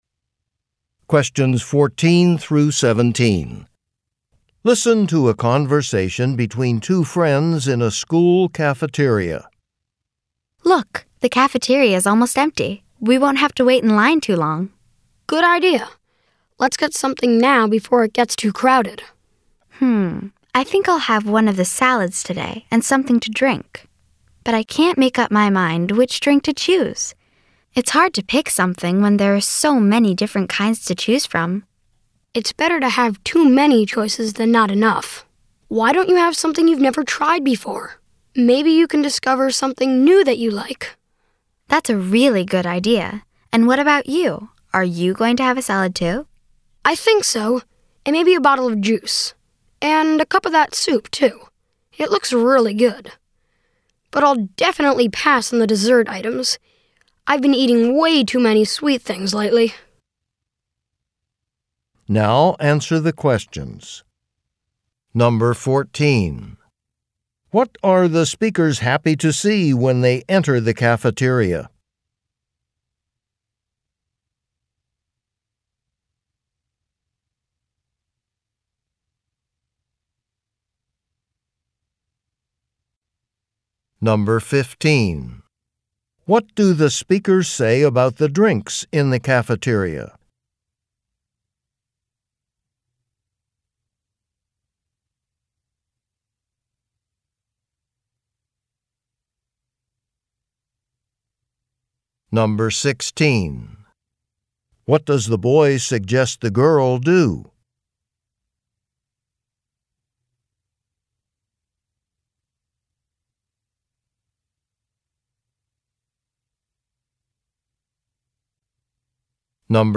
Conversation and Questions 14–17